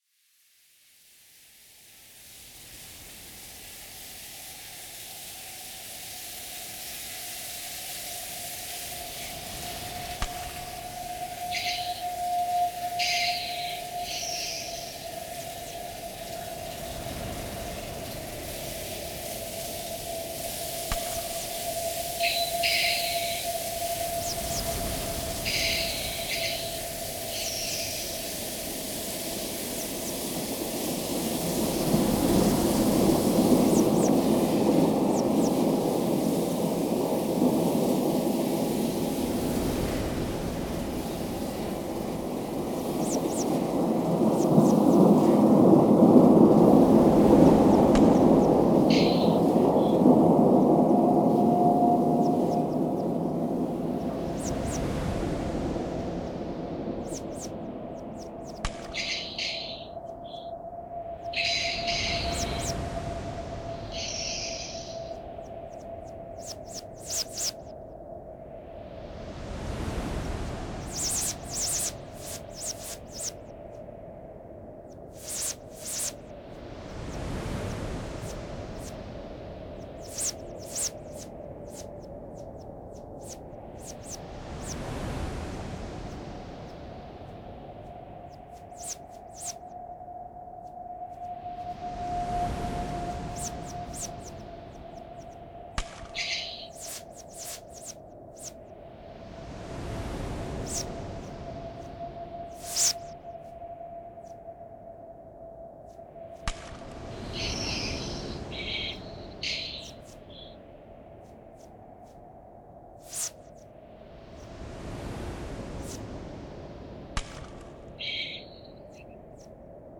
Sonoriste
Retrouvez l'ambiance sonore illustrée par la piste